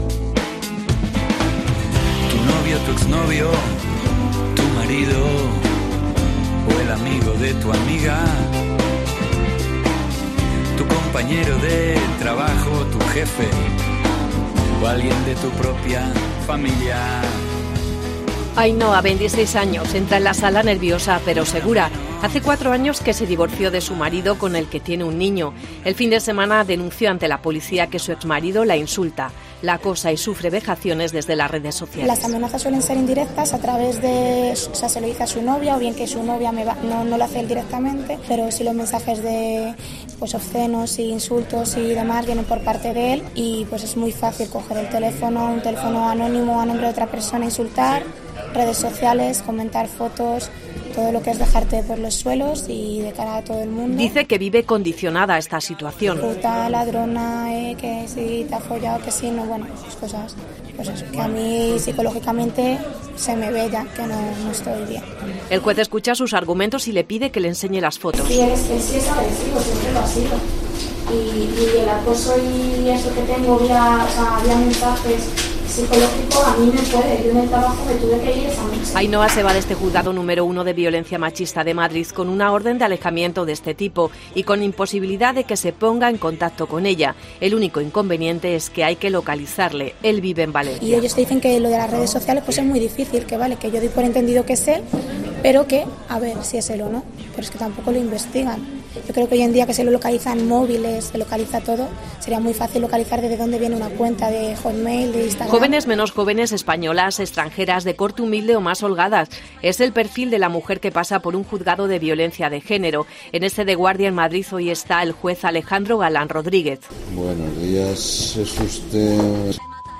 Mediodía COPE Un día en la sala de un juzgado de violencia machista. Reportaje